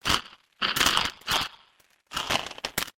咀嚼脆脆的食物
描述：我在吃饼干时录制，可以是任何类型的脆脆的食物，用索尼HDR PJ260V录制然后编辑于Audacity。
Tag: 弄碎 小吃 尖刻 嘎吱 用力咀嚼 格格 咀嚼 脆脆 曲奇饼 大嚼